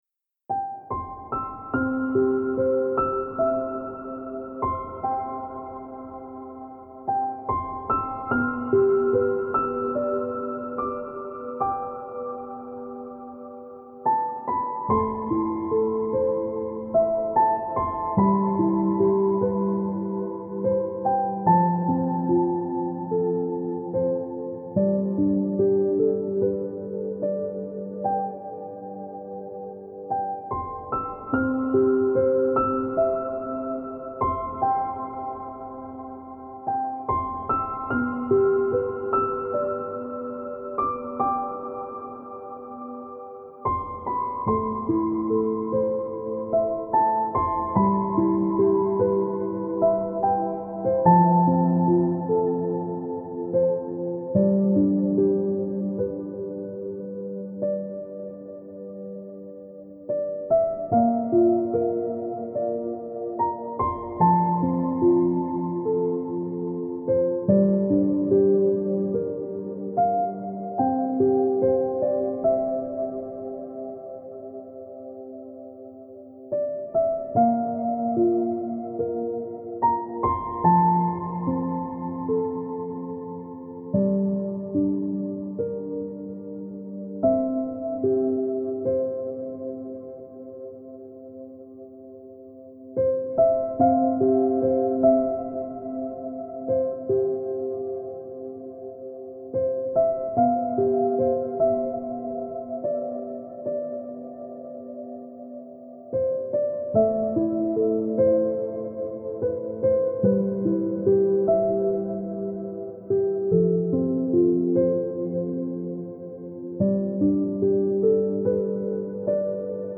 это вдохновляющая композиция в жанре неоклассики